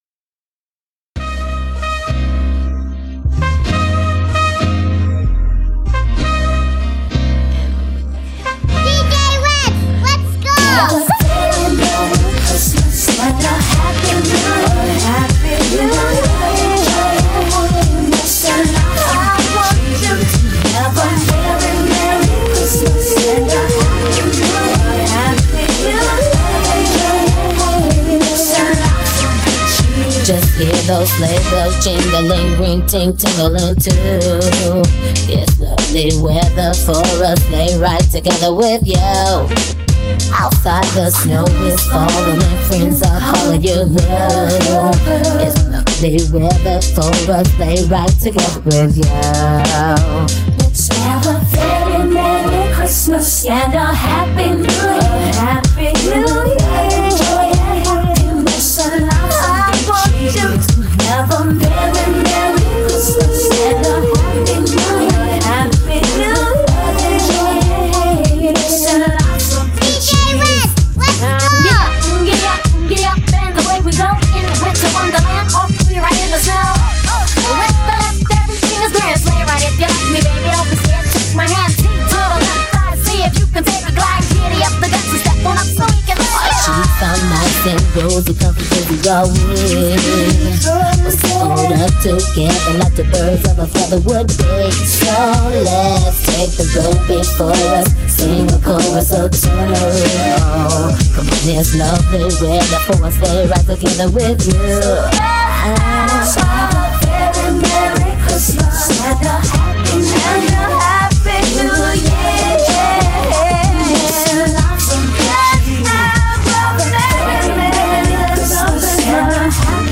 HipHop
RnB